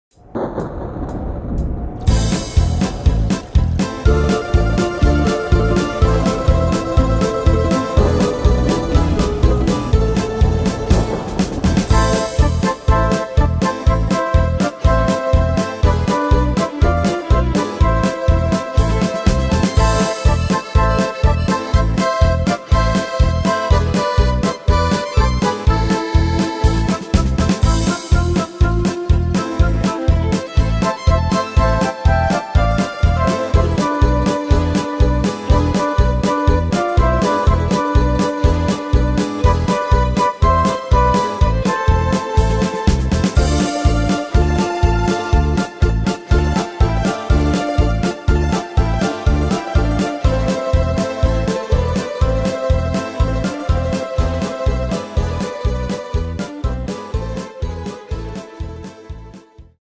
Tempo: 122 / Tonart: A-moll